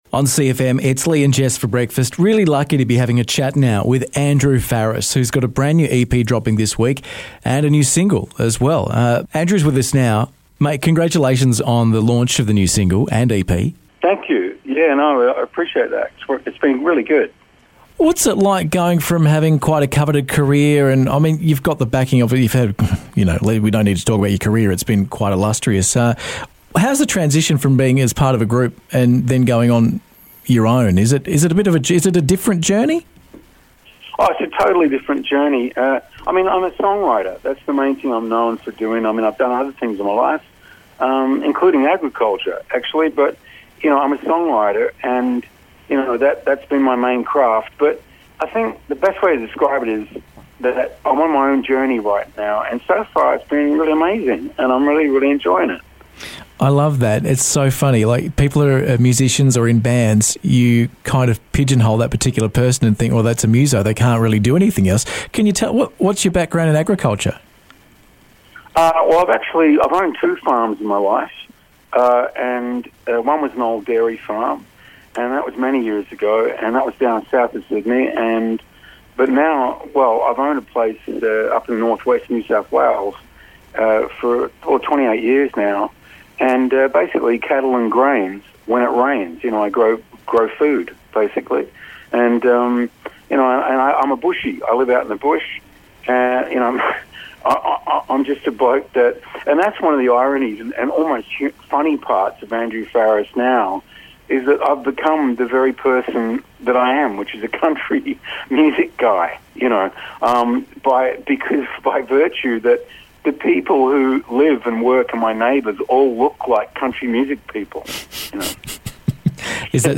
for a chat about his new EP and single which drop this week.